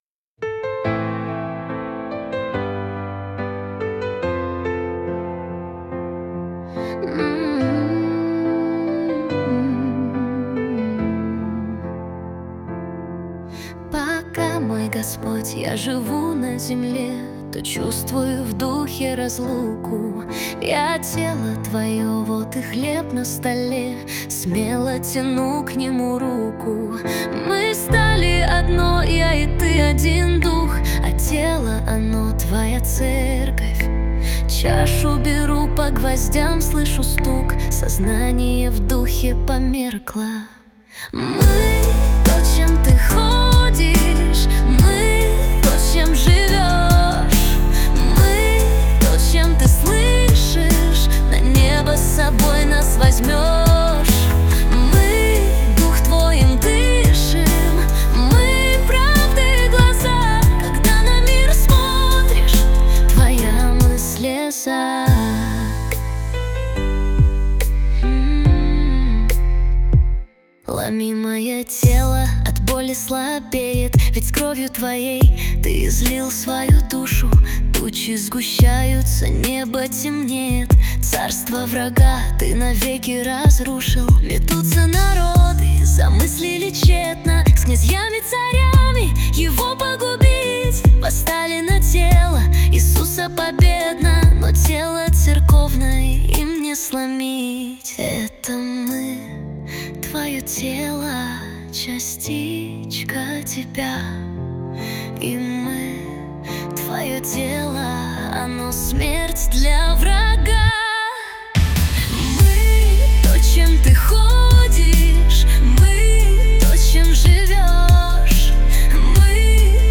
242 просмотра 1254 прослушивания 73 скачивания BPM: 70